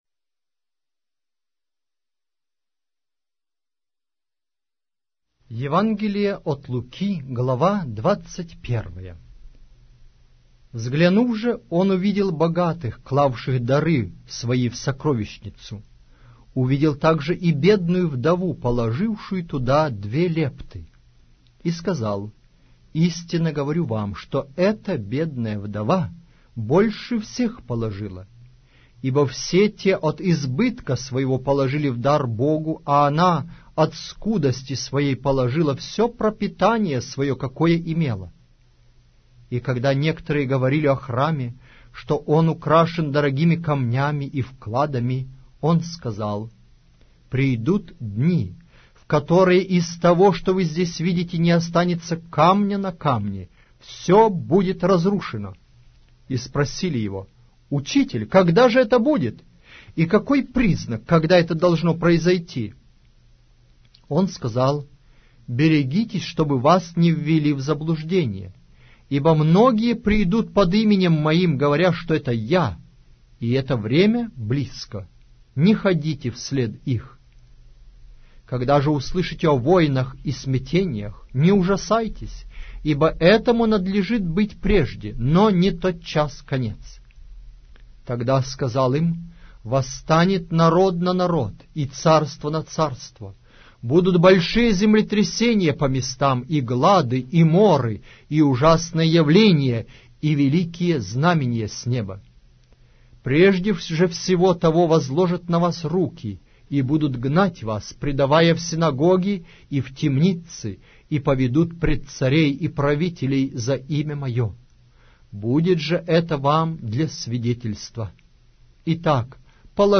Аудиокнига: Евангелие от Луки